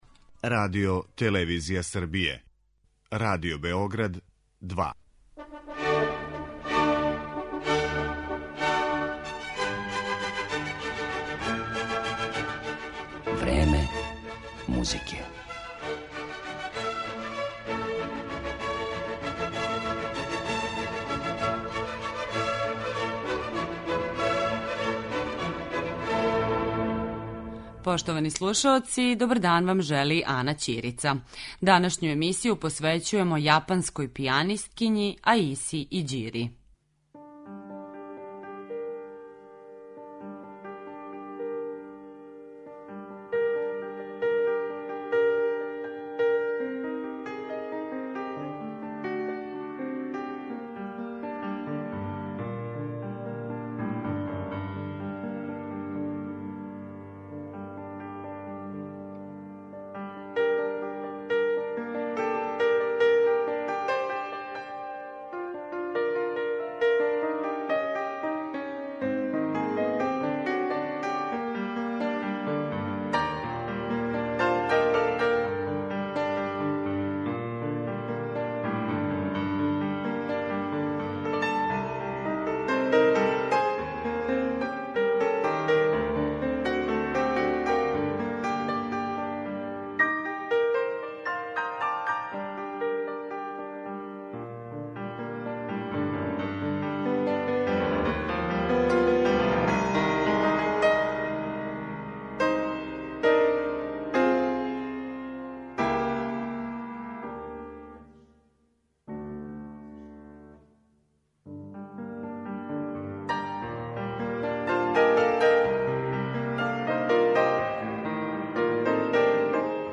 Емитоваћемо део разговора који смо снимили са њом 2017. године, пред наступ на фестивалу Keyboard Days Belgrade .